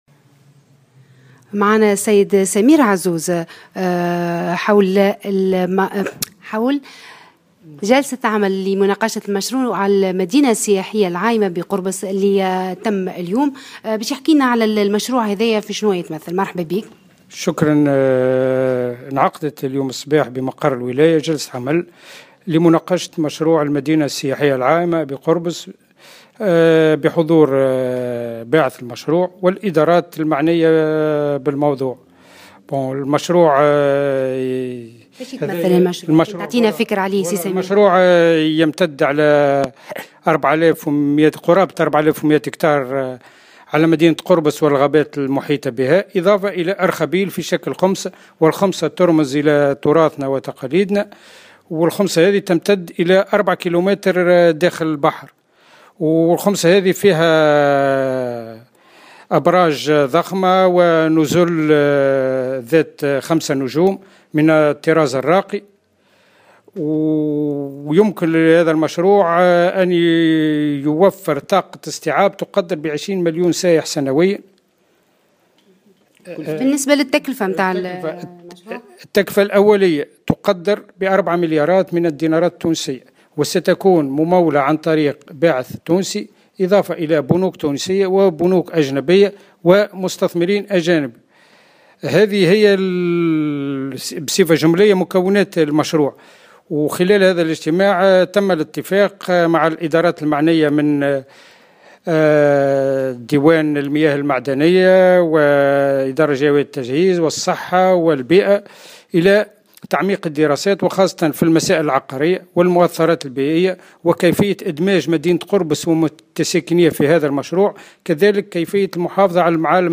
وأفاد كاتب عام ولاية نابل سمير عزوز، في تصريح للجوهرة أف أمن أن المشروع سيمتد على مساحة 4100 هكتار إضافة إلى ارخبيل على شكل "خُمسة" المستوحى من التقاليد التونسية.